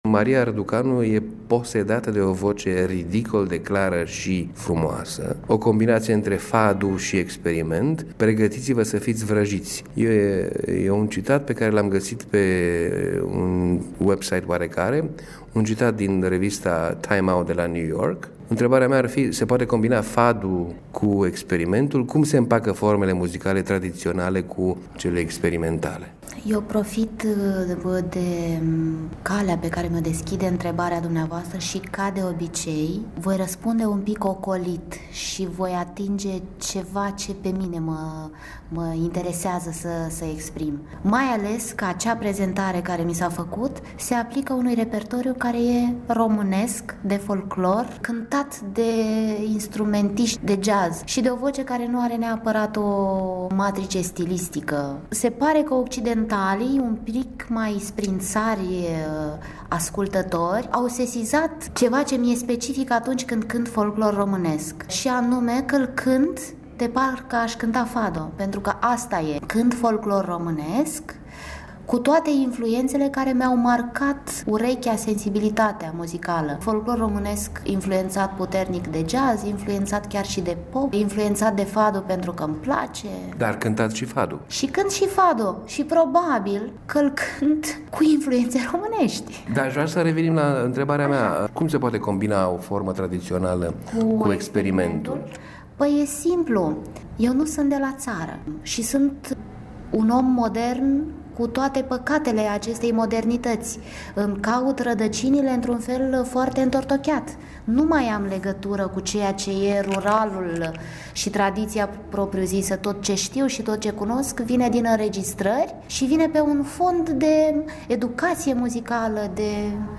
Interviu cu interpreta Maria Răducanu.
Maria Răducanu acompaniată de ghitaristul Marc Ribot